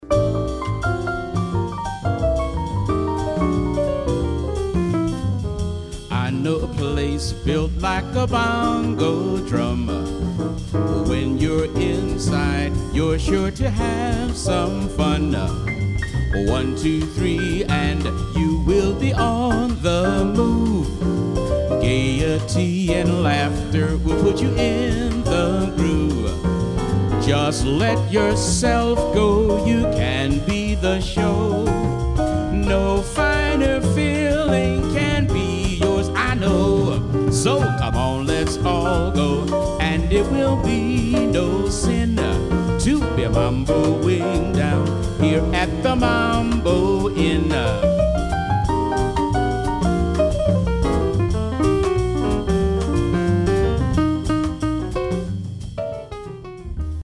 Instrumental Sampler Vocal Sampler